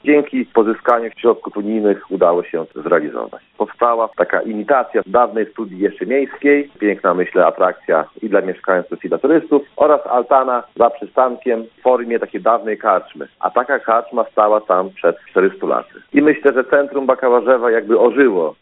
– Ustawione są w miejscu dawnej historycznej karczmy, która funkcjonowała od powstania miasteczka, czyli od 16. wieku – – mówi Tomasz Naruszewicz, wójt gminy Bakałarzewo.